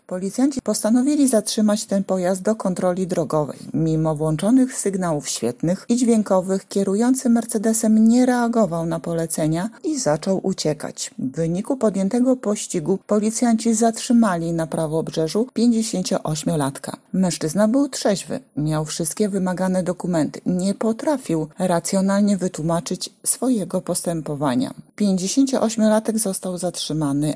mówi komisarz